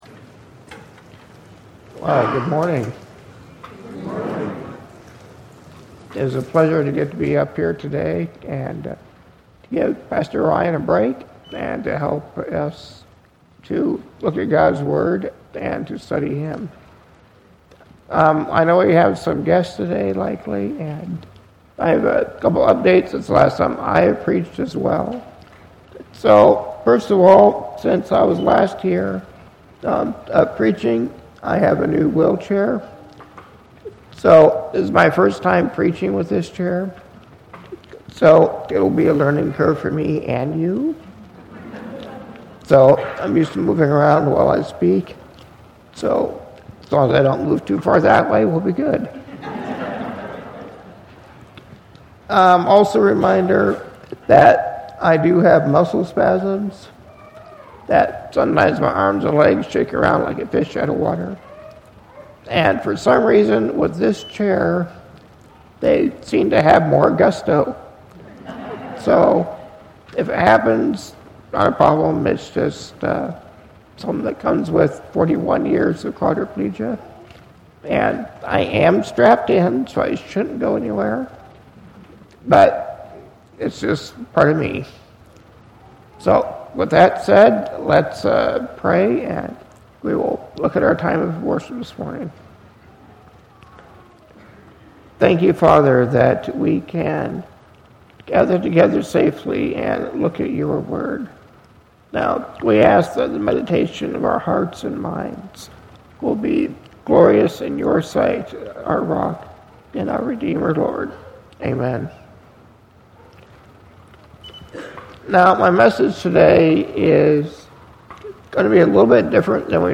Know the Source - Presented at Cornerstone Church